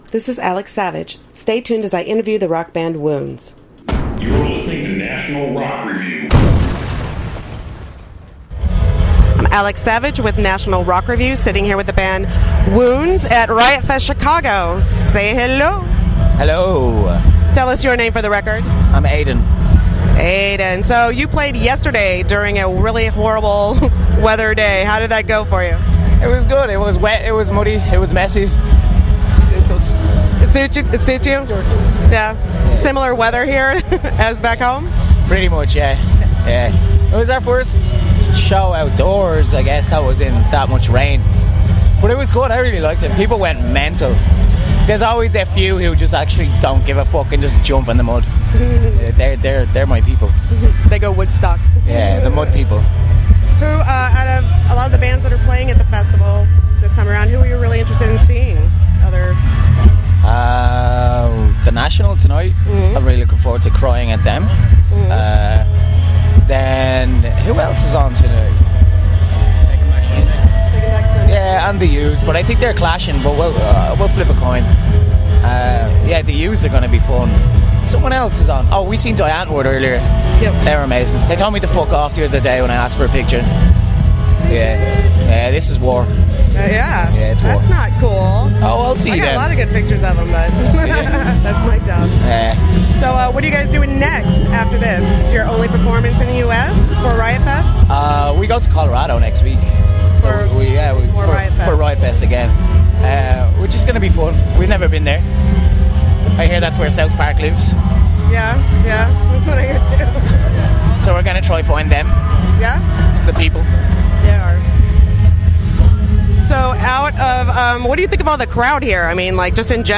Irish rock bandÂ WoundsÂ spoke to NRR at Riot Fest Chicago about their experience at the festival and their upcoming album and tour.
Interview with Wounds: